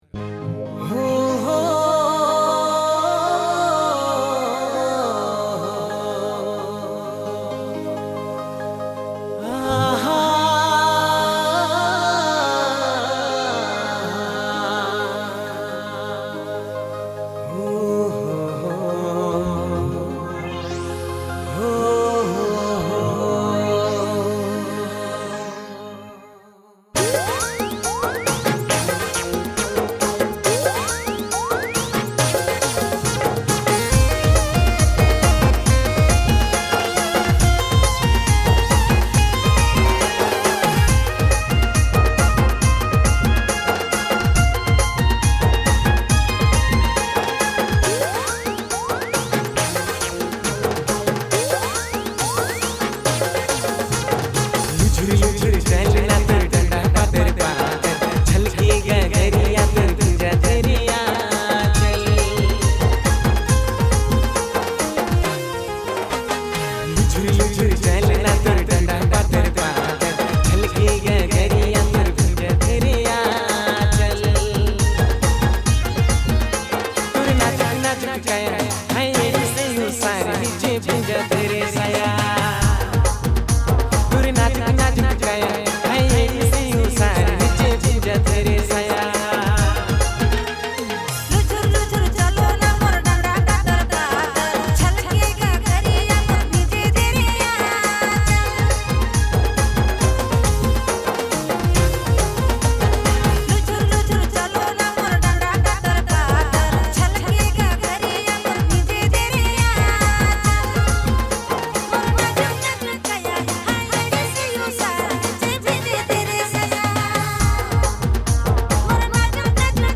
Dj Remixer
January Months Latest Nagpuri Song